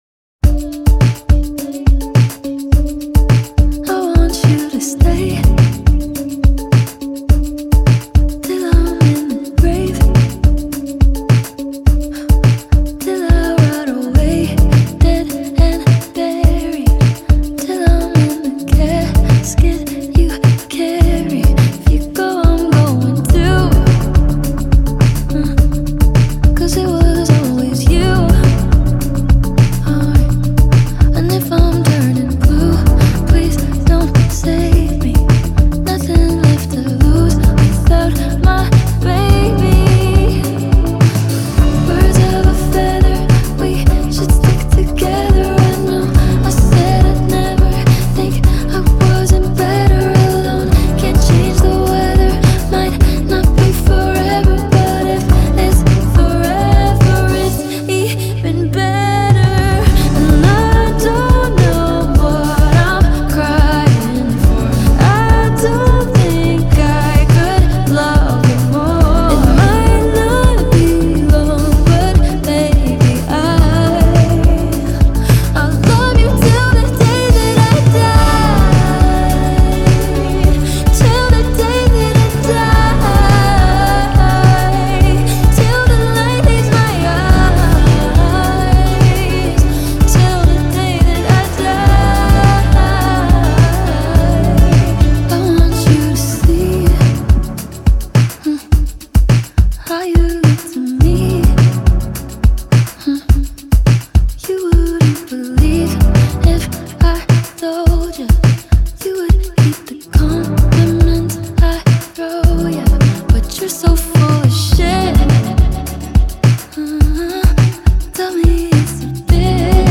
Живые инструменты все.